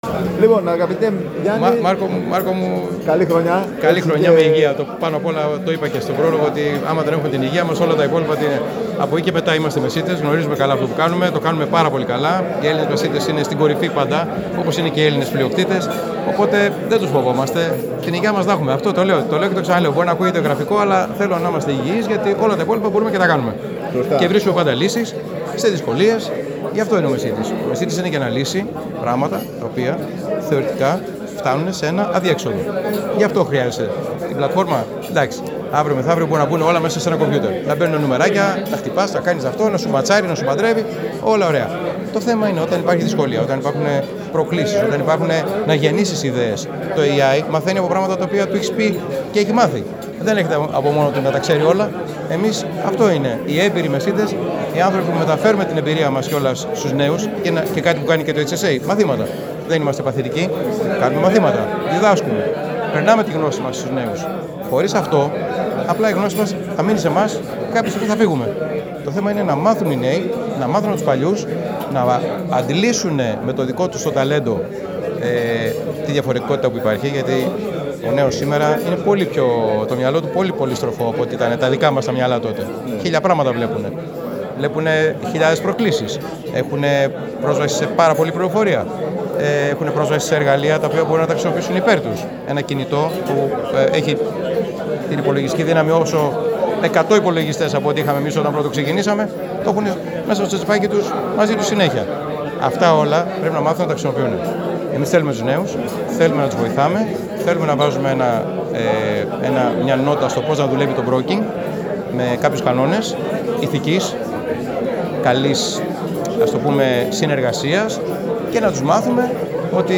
Από την εκδήλωση κοπής της Πρωτοχρονιάτικης πίτας του Συνδέσμου στη Ναυτιλιακή Λέσχη Πειραιώς την Δευτέρα 09 Φεβρουαρίου